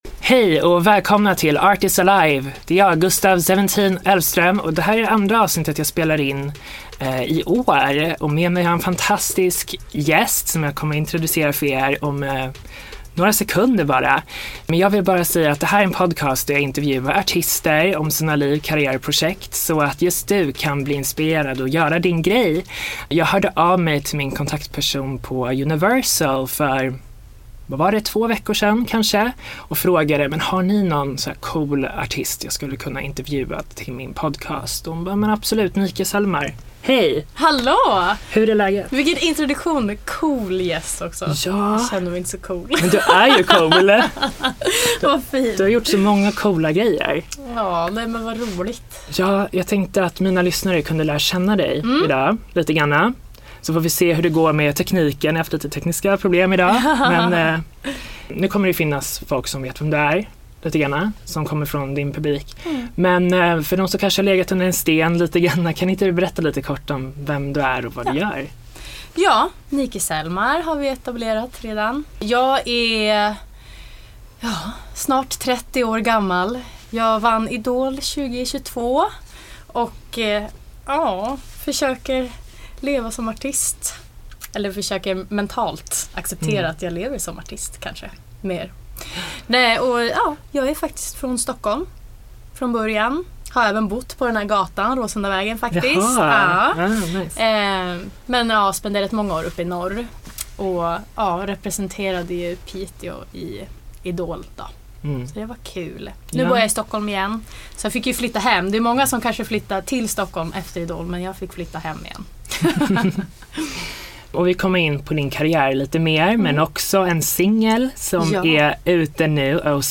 Tack till Universal Music Sweden som hjälpte mig med intervjun.